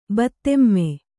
♪ battemme